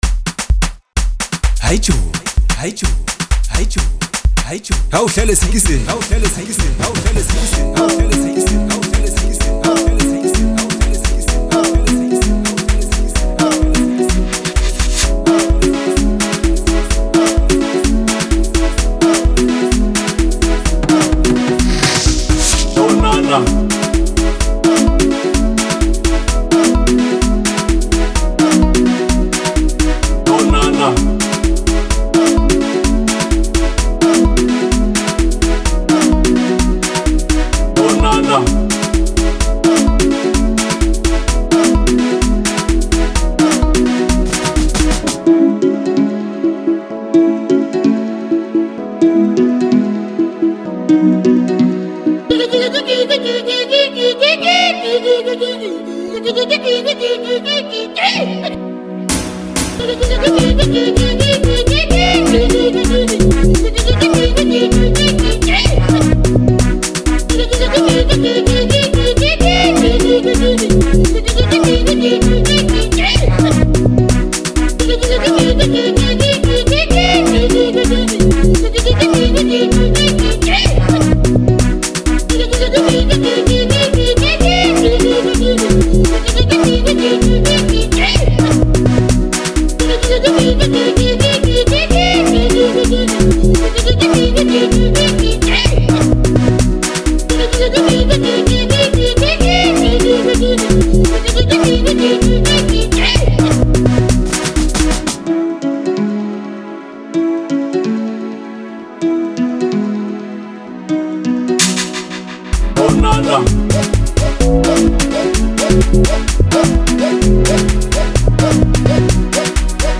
Bolo HouseLekompo